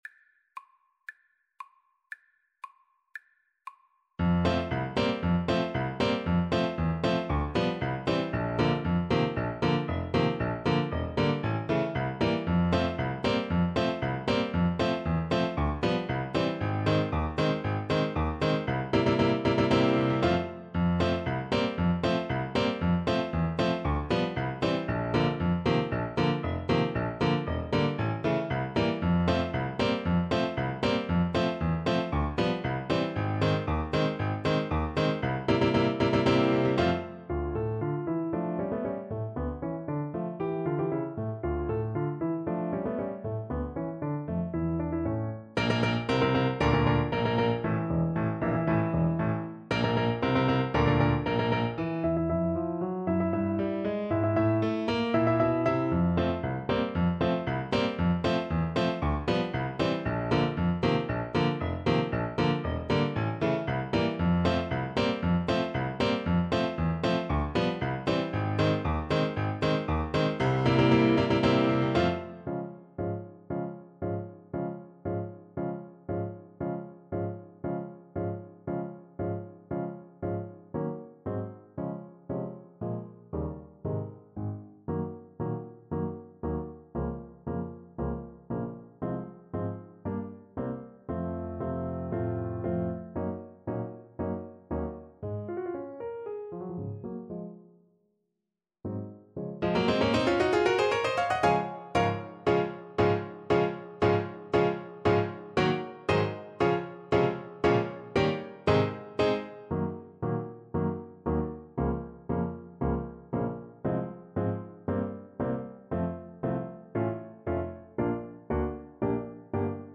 Play (or use space bar on your keyboard) Pause Music Playalong - Piano Accompaniment Playalong Band Accompaniment not yet available transpose reset tempo print settings full screen
F major (Sounding Pitch) D major (Alto Saxophone in Eb) (View more F major Music for Saxophone )
Allegro giocoso =116 (View more music marked Allegro giocoso)
Classical (View more Classical Saxophone Music)